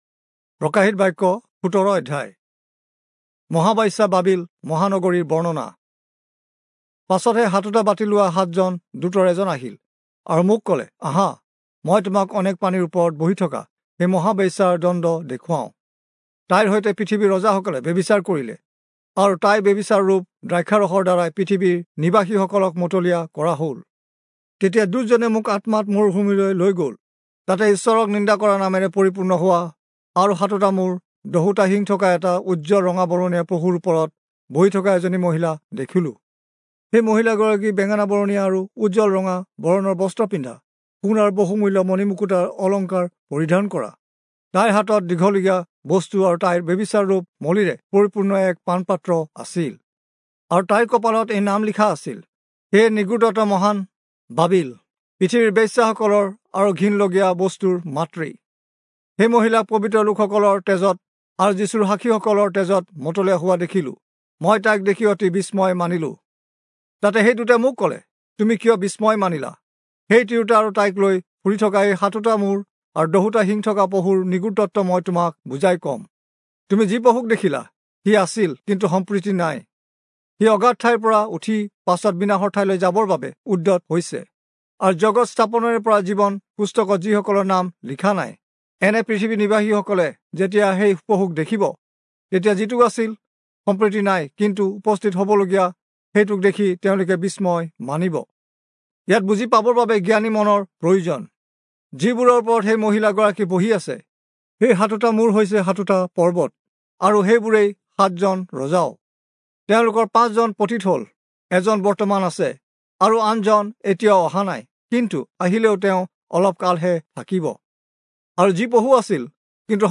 Assamese Audio Bible - Revelation 5 in Ocvbn bible version